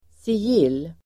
Ladda ner uttalet
Uttal: [sij'il:]